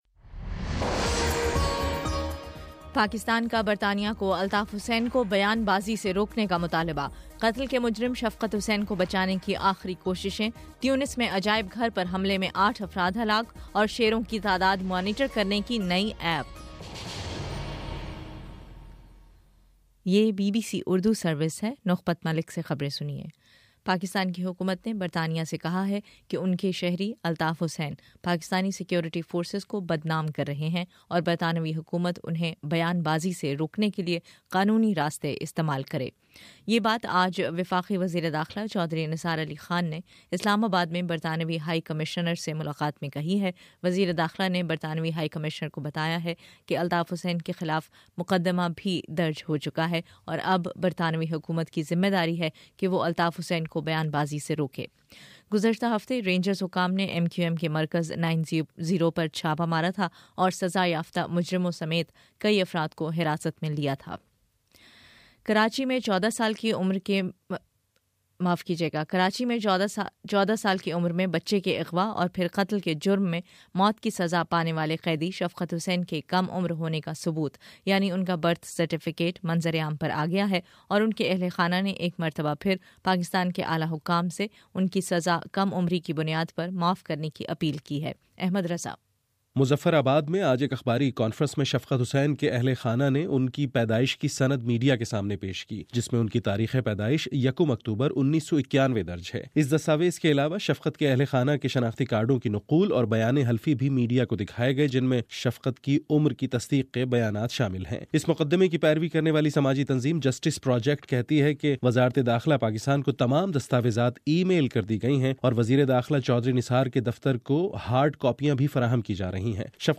مارچ 18: شام سات بجے کا نیوز بُلیٹن